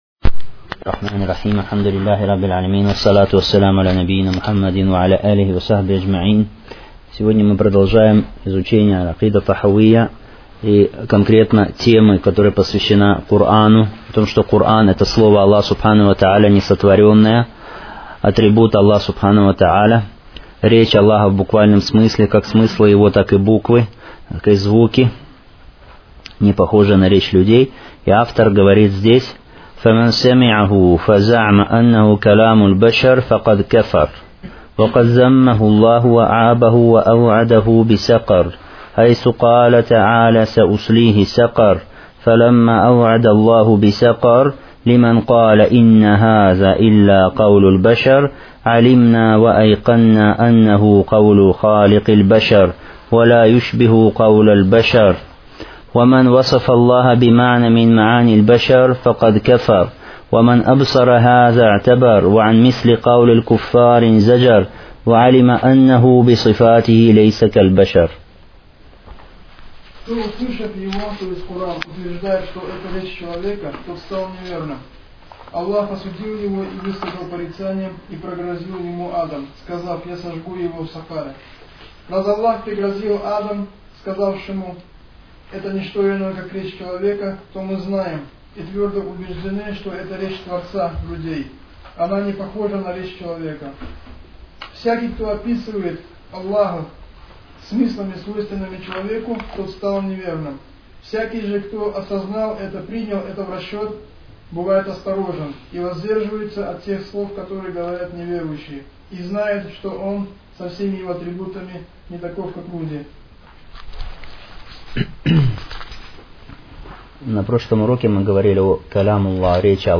Серия уроков в пояснении книги «Акида Тахавия».